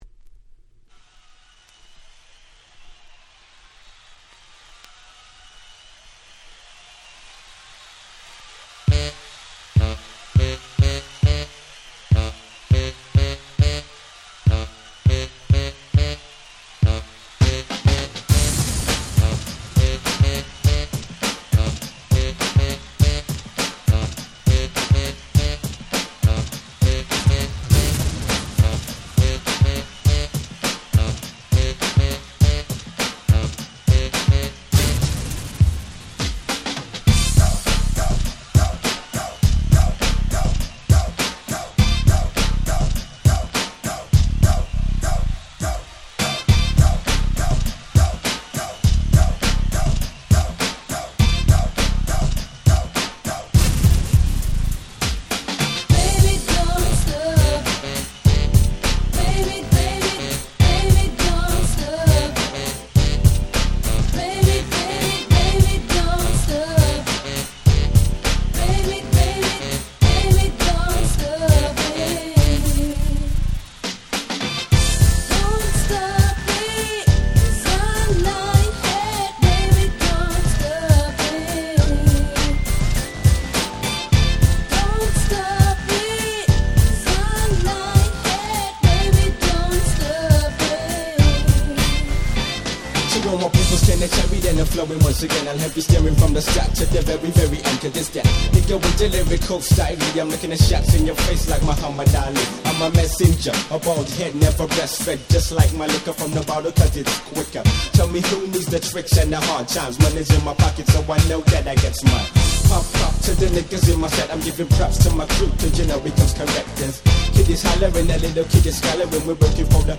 サビで女性Vocalも絡む大変キャッチーなユーロ歌ラップ物！！
Euro歌Rap最高峰。
ユーロ キャッチー系